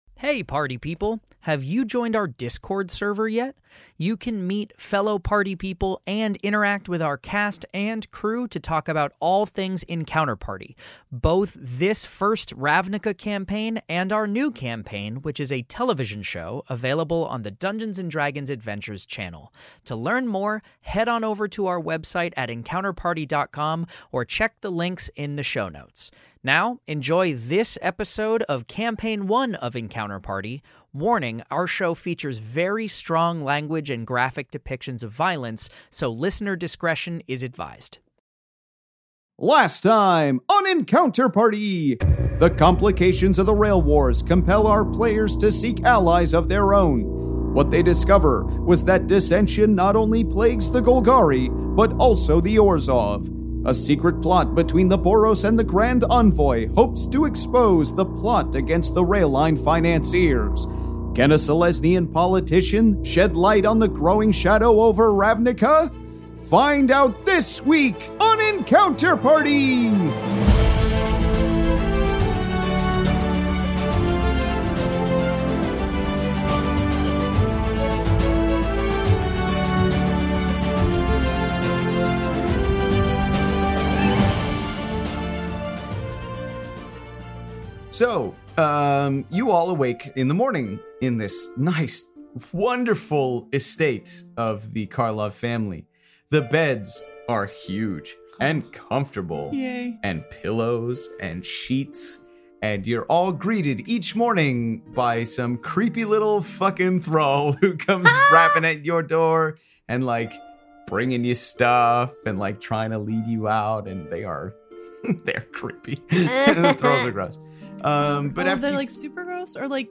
Fantasy Mystery Audio Adventure
five actors and comedians